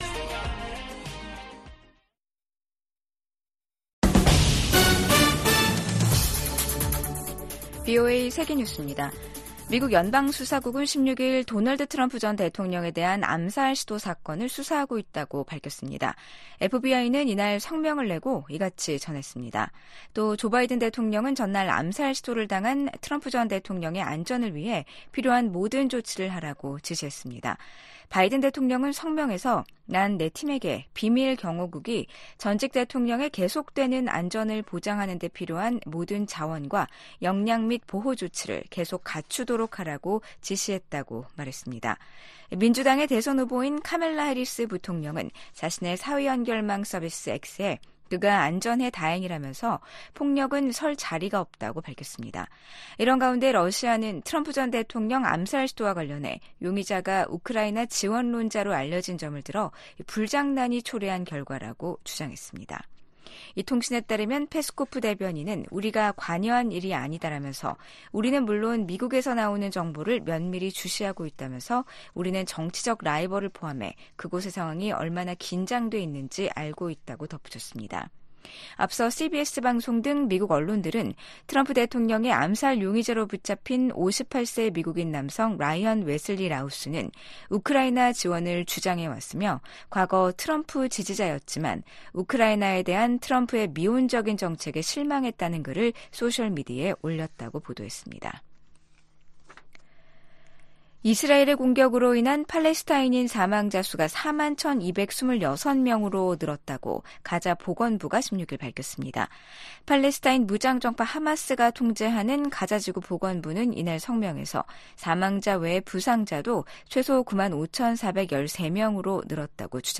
VOA 한국어 아침 뉴스 프로그램 '워싱턴 뉴스 광장' 2024년 9월 17일 방송입니다. 미국 백악관은 북한이 우라늄 농축시설을 공개한 것과 관련해 북한의 핵 야망을 계속 감시하고 있다고 밝혔습니다. 북한은 다음달 초 최고인민회의를 열고 헌법 개정을 논의한다고 밝혔습니다.